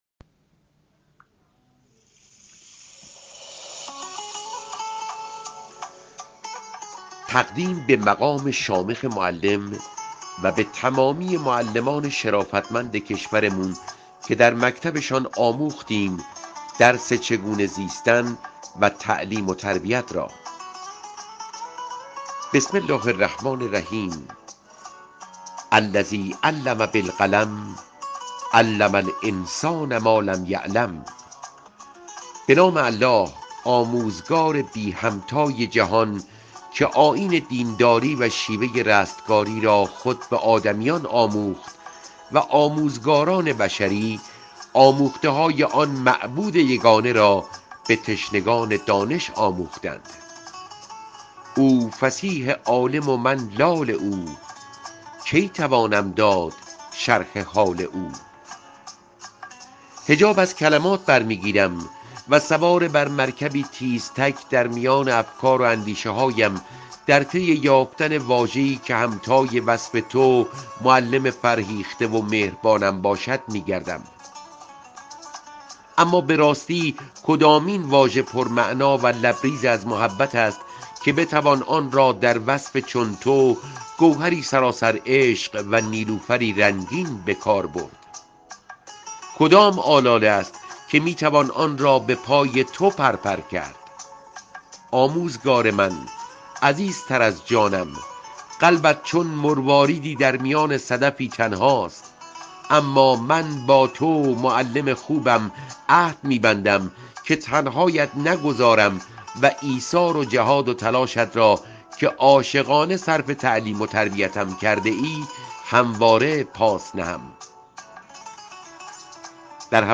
خوانش متنی را درباره این موضوع در اختیار ایکنا قرار داده است که می‌شنویم